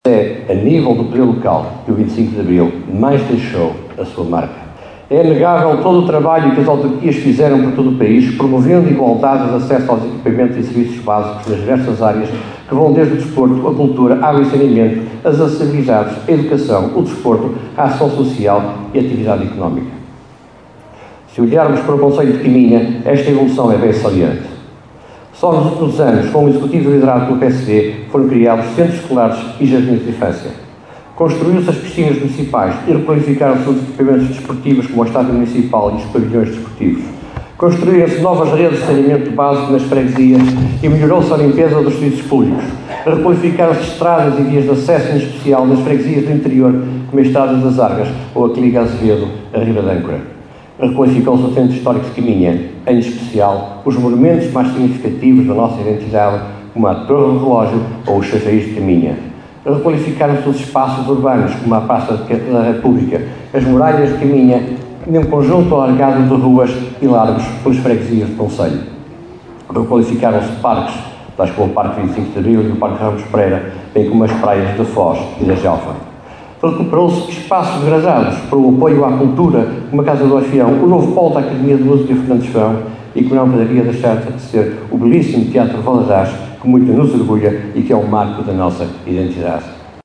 Foi com chuva que o 25 de Abril foi celebrado esta manhã em Caminha.